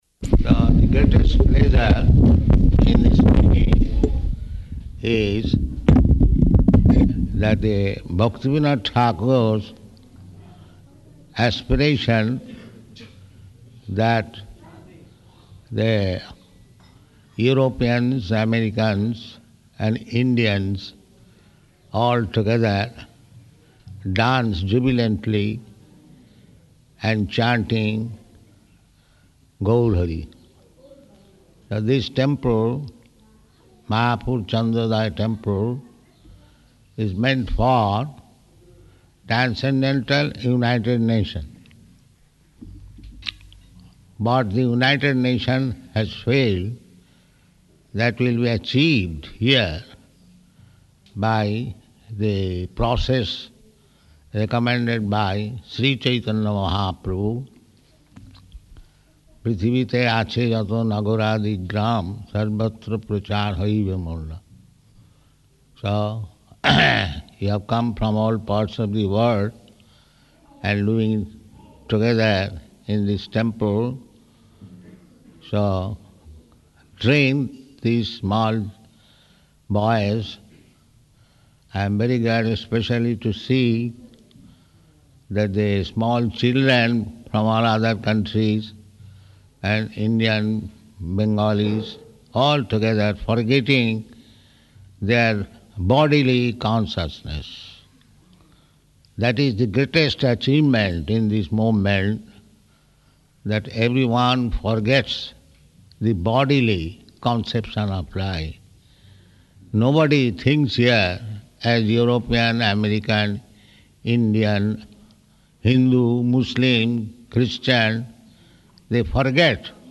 Speech [partially recorded]
Location: Māyāpur